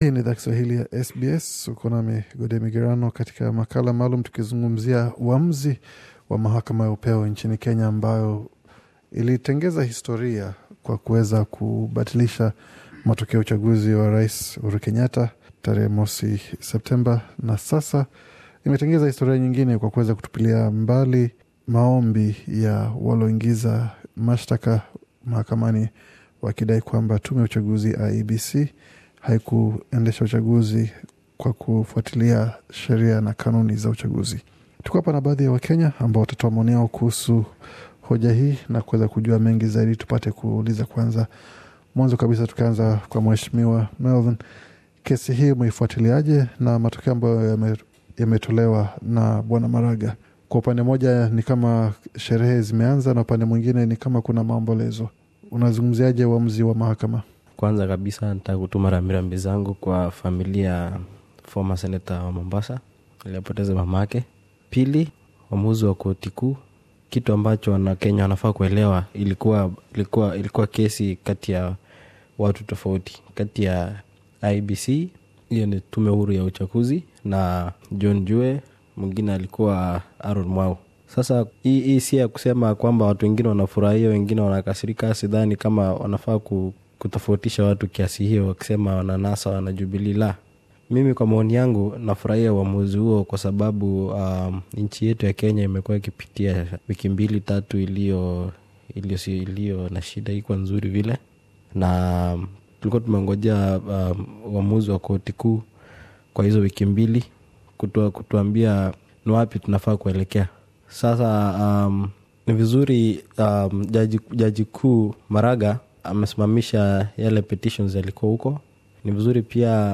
Mahakama ya juu ya Kenya, imetoa uamuzi kuwa Rais Uhuru Kenyatta alichaguliwa kihalali katika marudio ya uchaguzi wa urais uliyofanyika Oktoba 26 ambao ulisusiwa na upinzani. Wakenya wanao ishi Sydney, walichangia maoni yao kuhusu uamuzi huo wa mahakama na SBS Swahili.